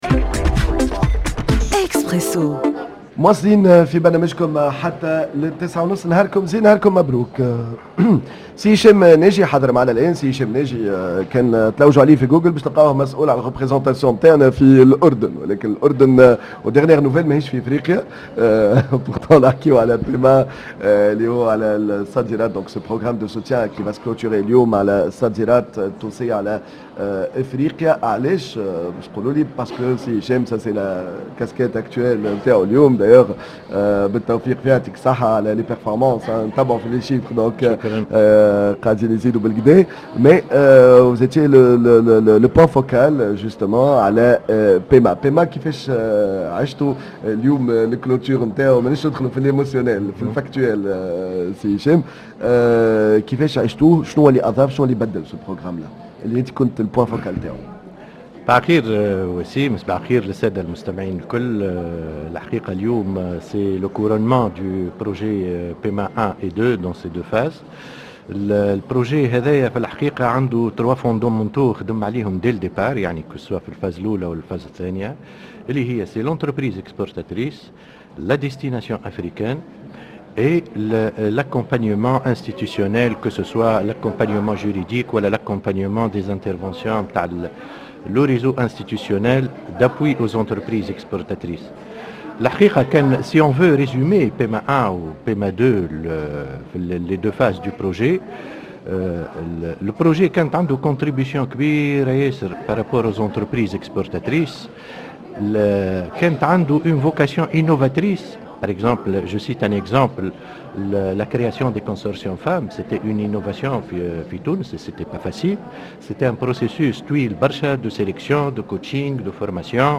dans un plateau spécial en direct du CEPEX